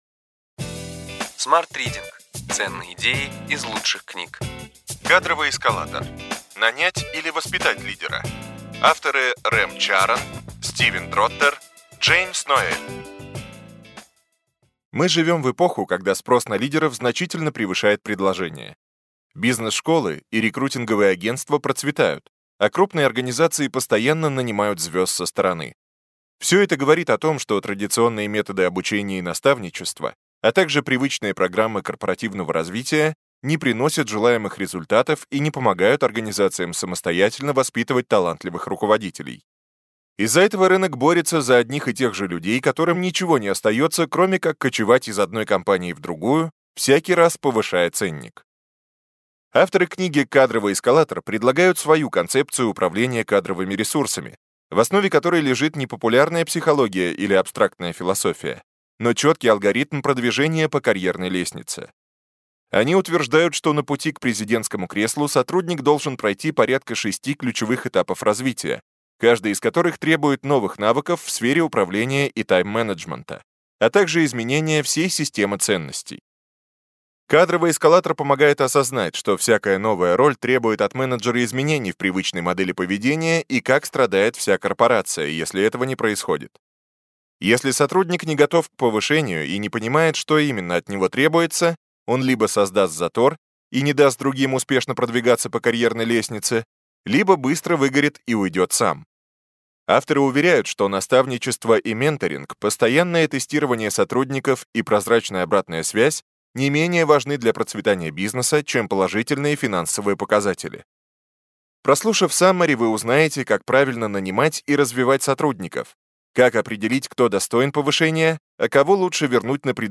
Аудиокнига Ключевые идеи книги: Кадровый эскалатор: нанять или воспитать лидера? Рэм Чаран, Стивен Дроттер, Джеймс Ноэль | Библиотека аудиокниг